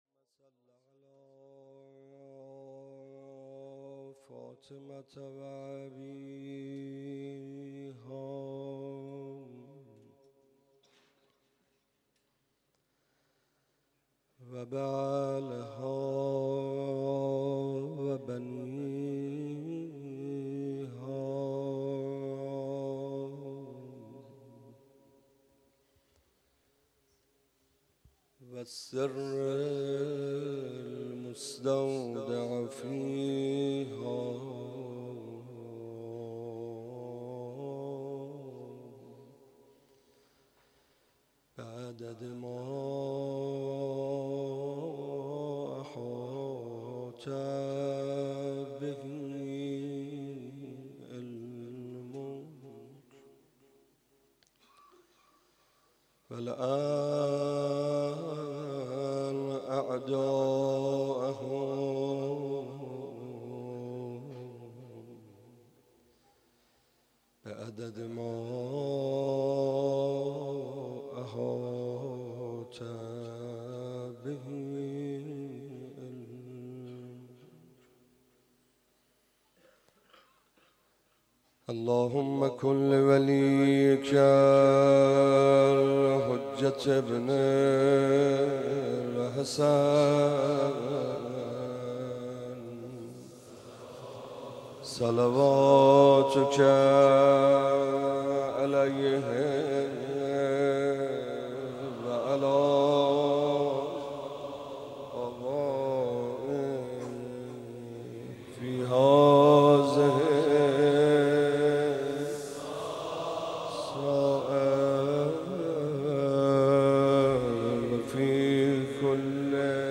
روضه حضرت ابالفضل العباس (ع)